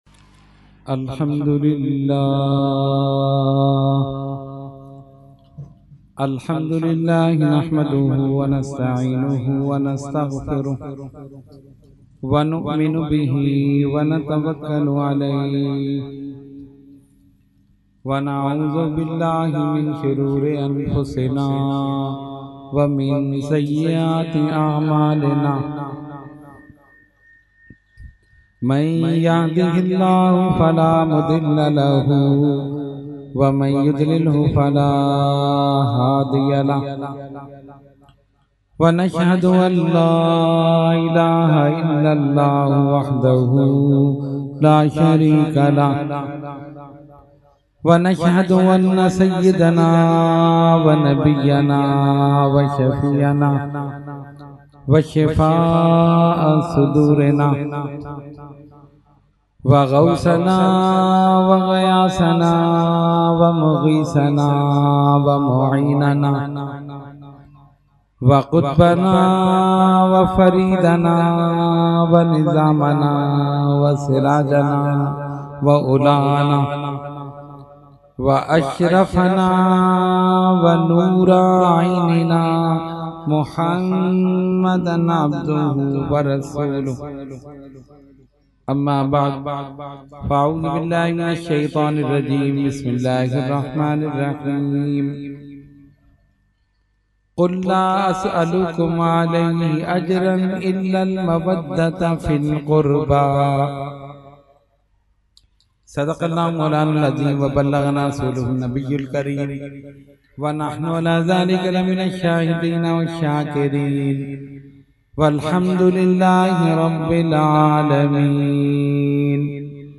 Category : Speech | Language : UrduEvent : Muharram 2019